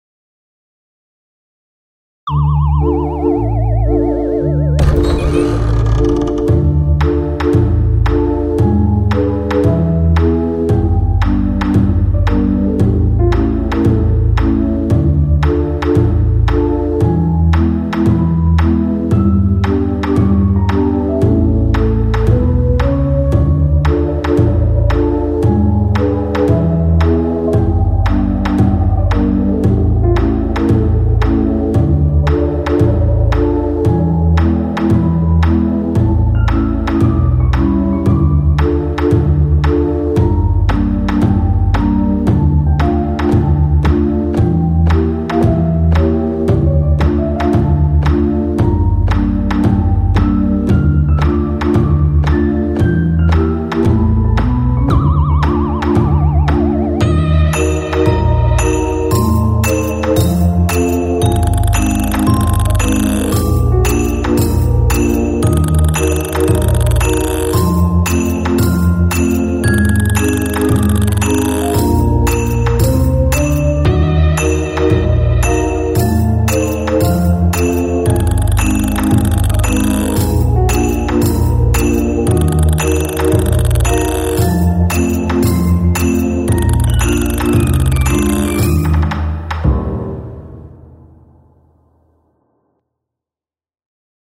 BGM
ショートホラー